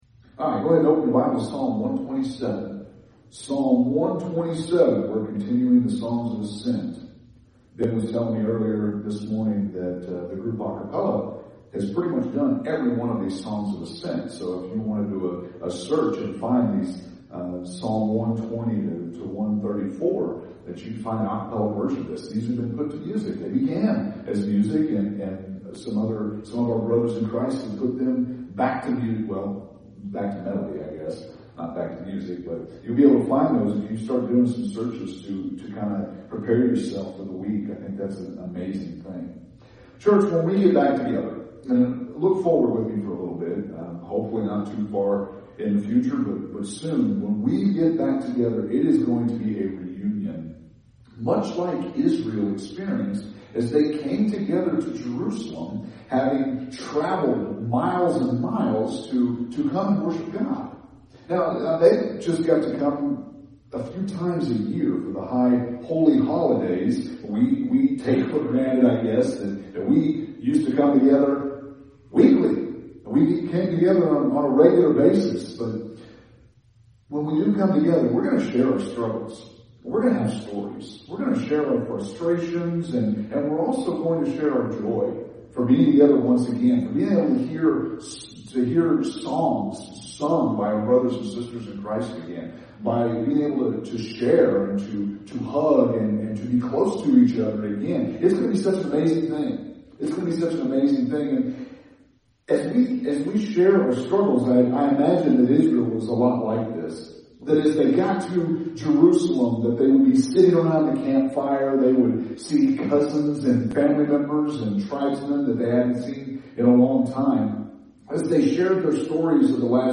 April 19th – Sermons